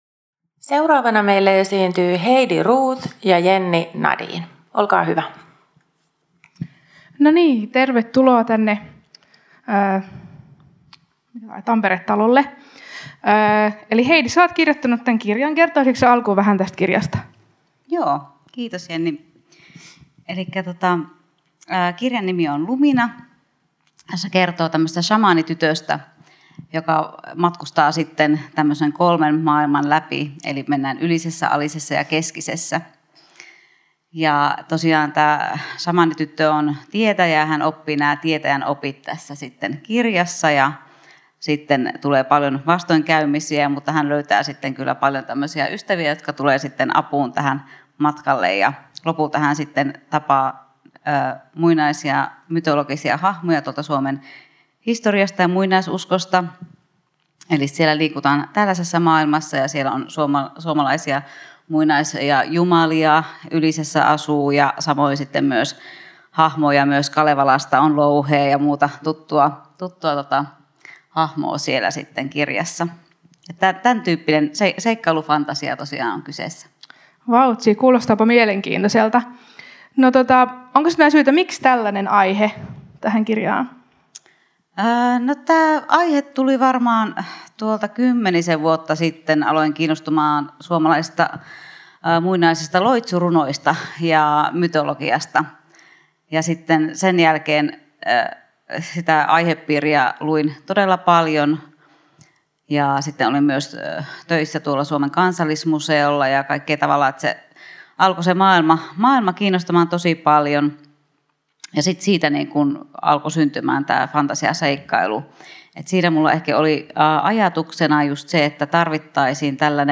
Ohjelma on taltioitu Tampereen Kirjafestareilla 2024.